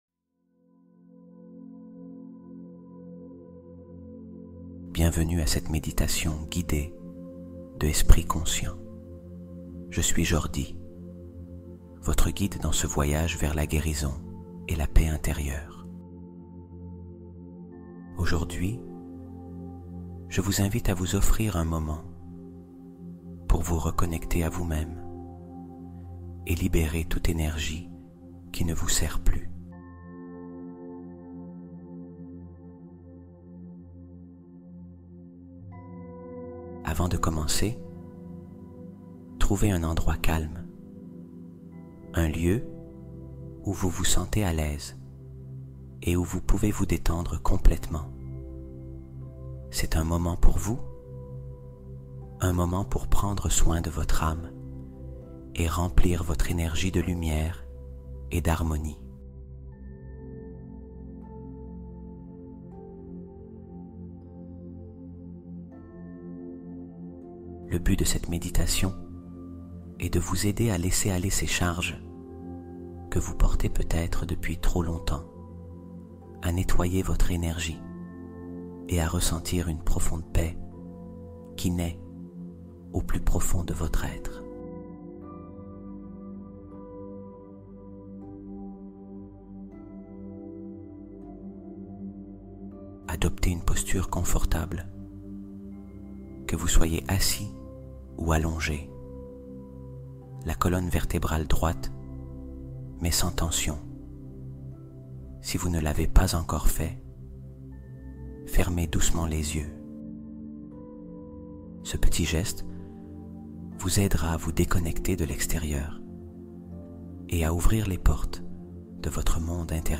Guérison quantique : hypnose et méditation profonde